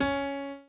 b_pianochord_v100l16-7o5cp.ogg